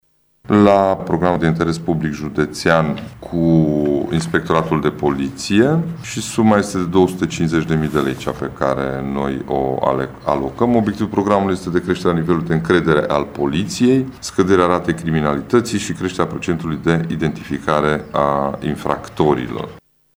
Președintele CJ Mureș, Ciprian Dobre: